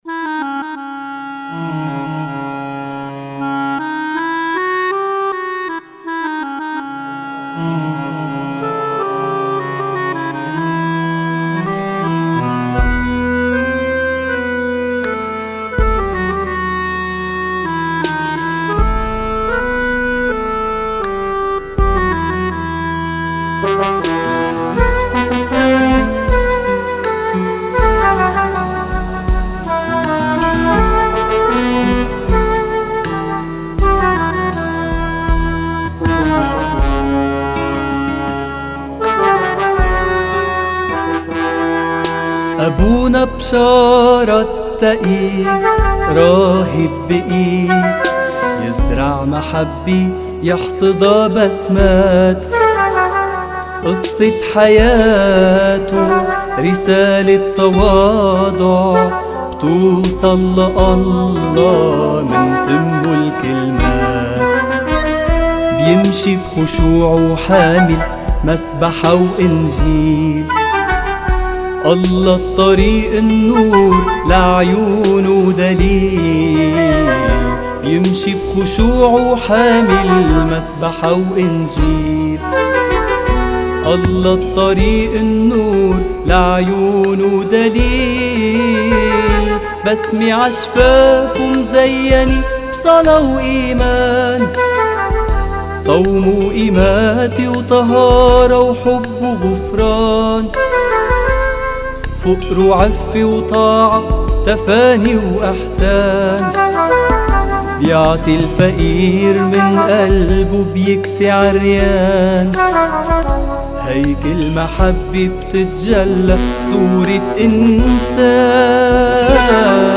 - توزيع وتسجيل استديو أندره عطا